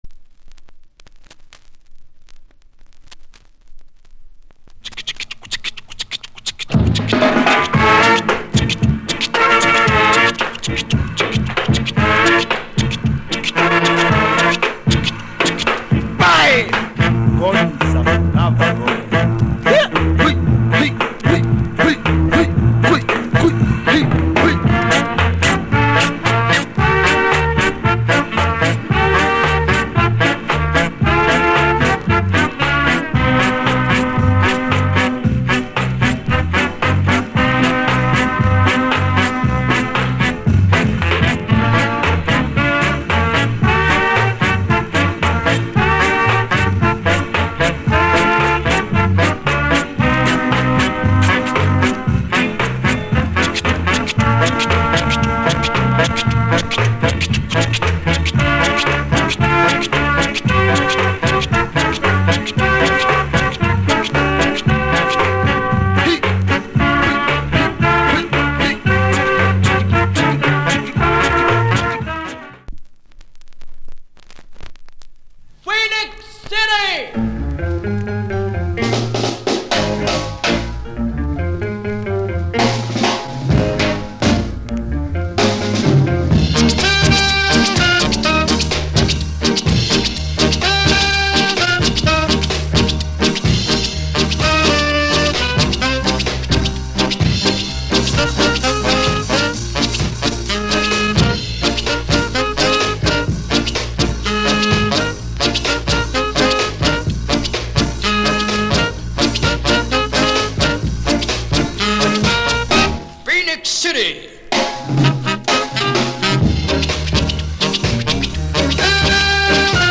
Genre Ska / Male Vocal Female Vocal Inst